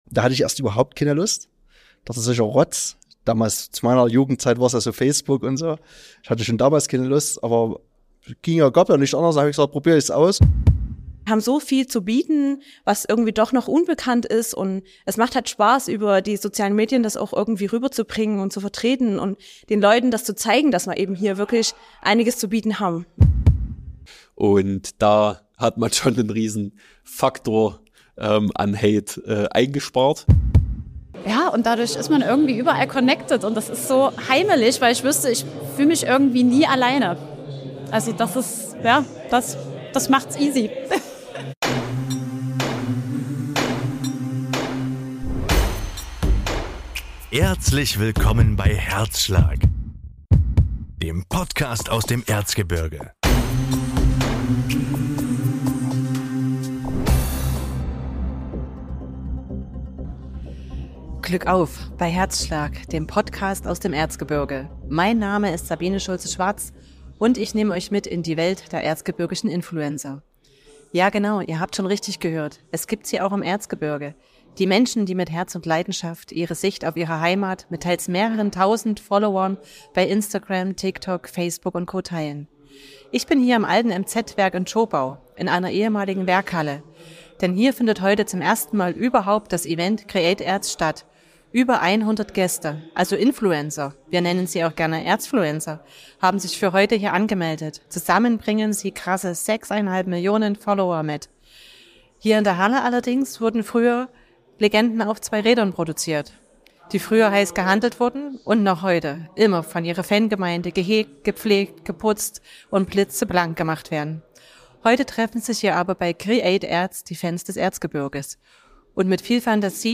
Mit der neuen hERZschlag Podcast-Episode hören wir in das erste und exklusive creat.ERZ Event aus den ehemaligen Werkhallen des Motorrad-Herstellers MZ in Zschopau hinein.